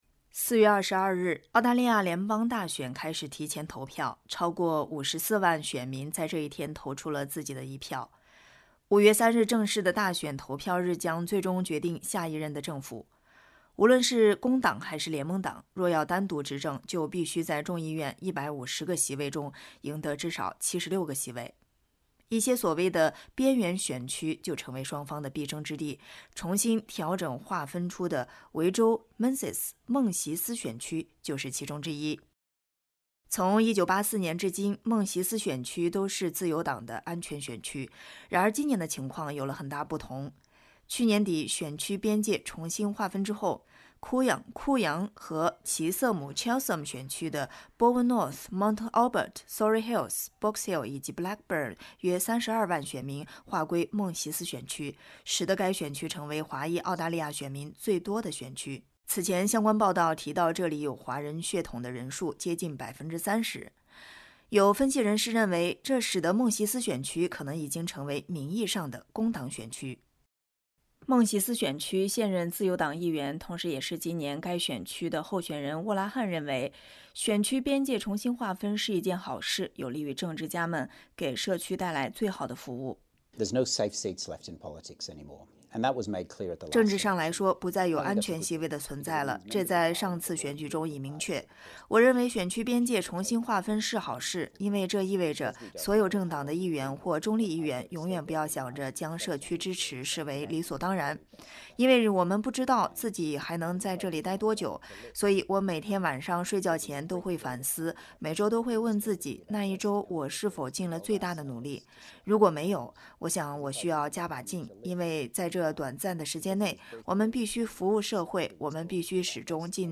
联邦大选在即，维州孟席斯（Menzies）和奇瑟姆（Chisholm）选区候选人如何看待选区边界调整以及华人选民关心的问题？点击音频收听采访。